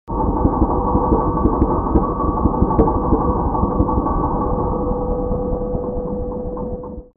دانلود آهنگ دریا 21 از افکت صوتی طبیعت و محیط
دانلود صدای دریا 21 از ساعد نیوز با لینک مستقیم و کیفیت بالا
جلوه های صوتی